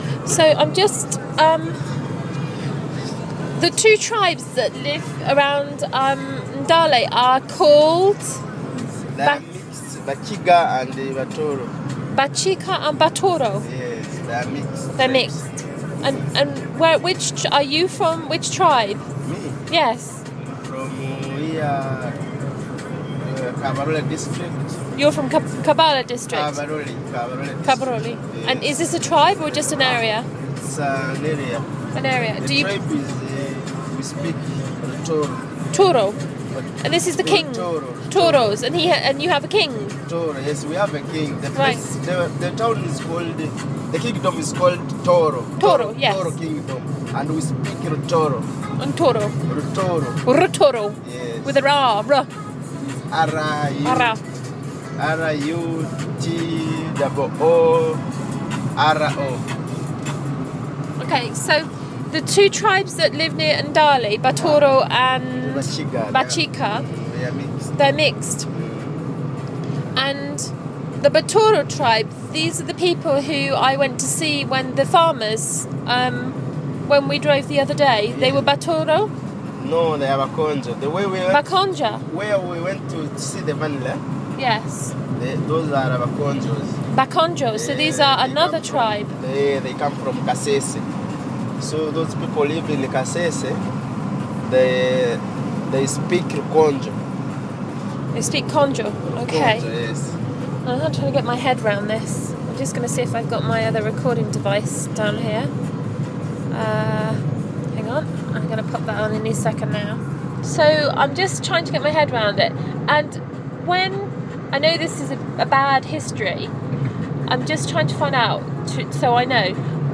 Chatting to my driver about the various tribes in Uganda